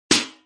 metal1.mp3